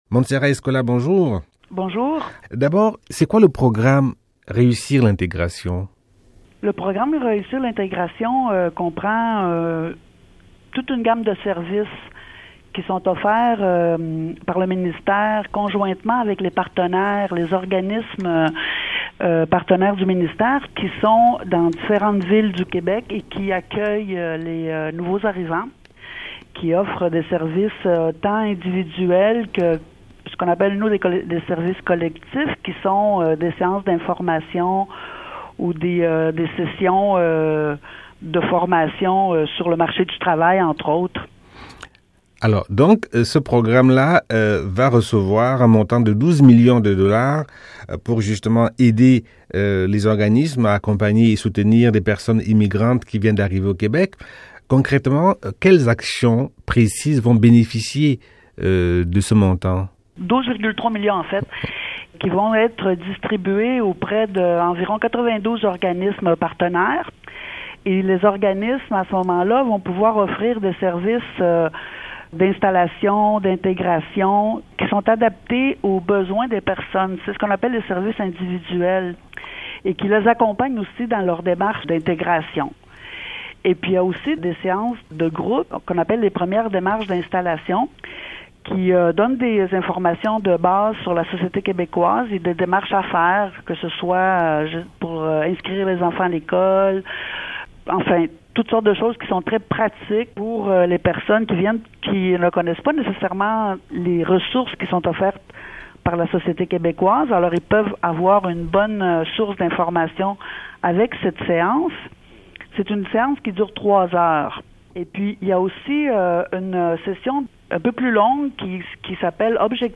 Elle donne plus de précisions su l’usage qui sera fait des 12,5 millions.